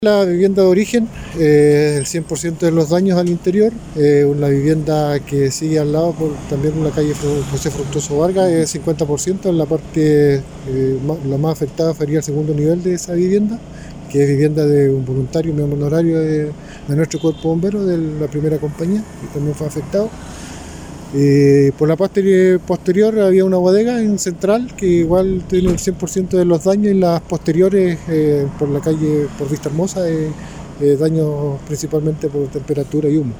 Además, una de las casas afectadas por el incendio corresponde a un miembro honorario de la institución expresó el oficial bomberil.